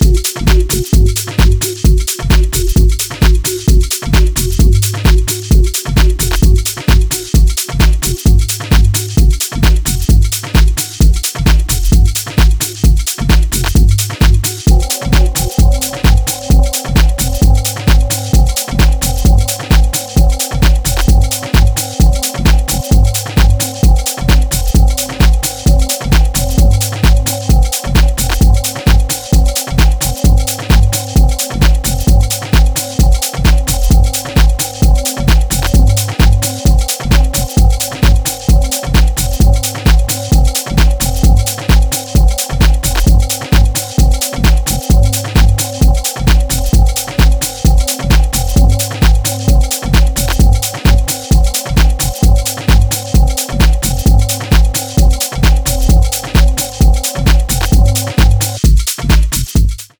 ダークなシンセが淡々とフロアに並走する
ハウスに流れるラテンの遺伝子をモダンなタッチで強調。